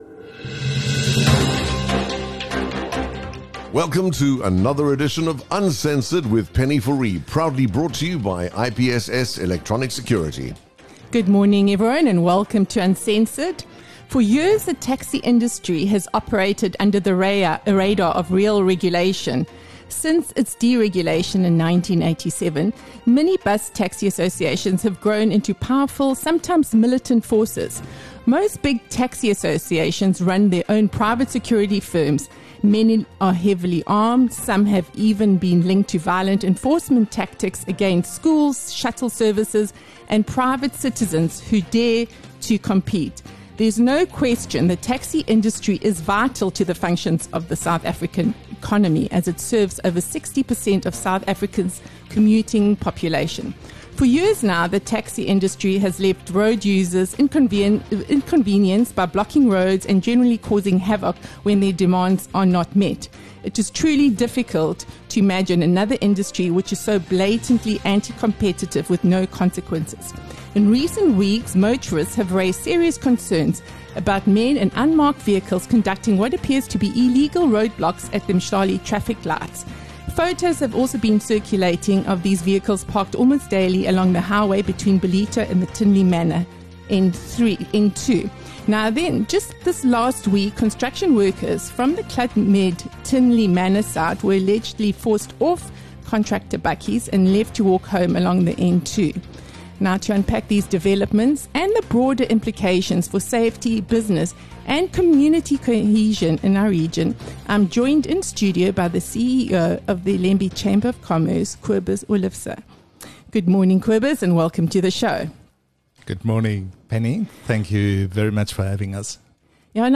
Inside the Transport Tensions: A Conversation